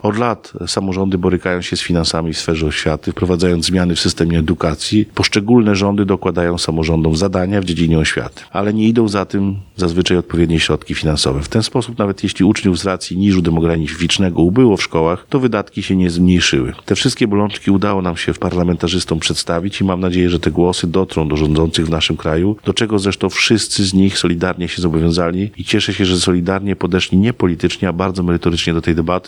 Debata poświęcona edukacji odbyła się w Urzędzie Gminy Ełk.